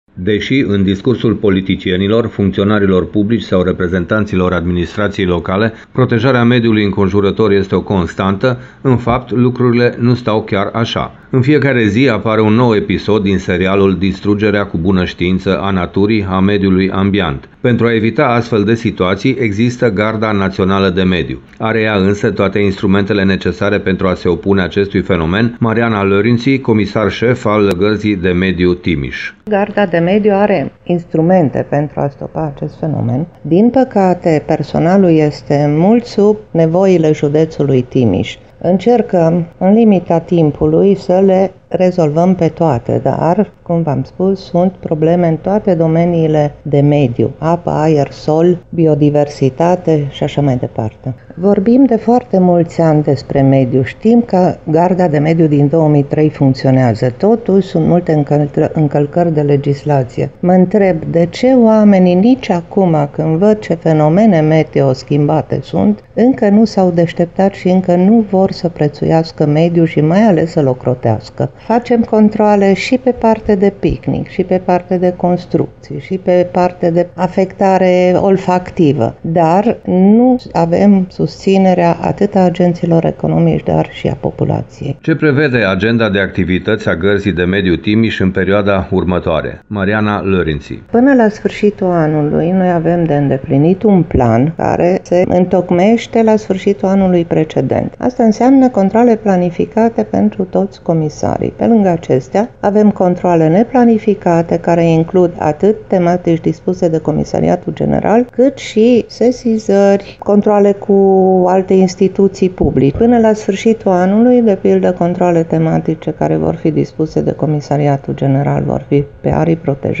UPDATE sinteza emisiunii